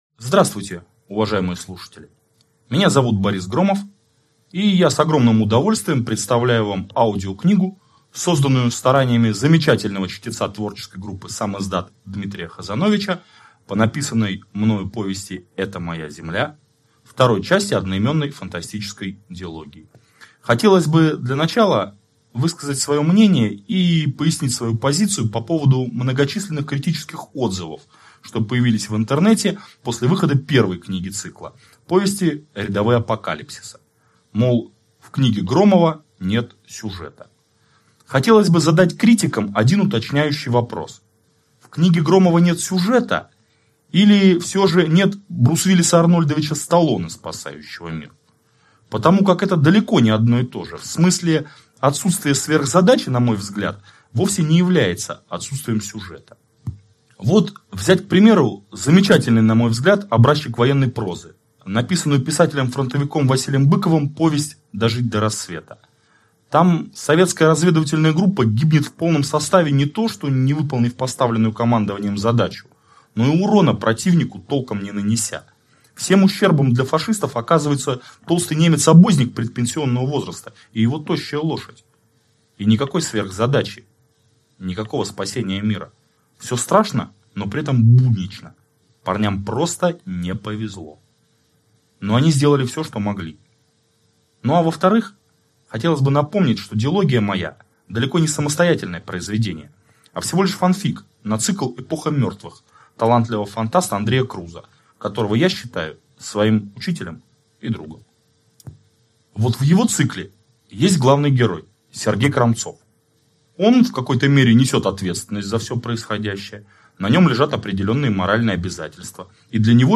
Аудиокнига Это моя земля!